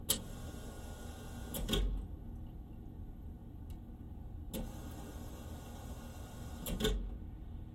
马桶盖向上
描述：马桶盖向上
Tag: 厕所 达\开/关 淋浴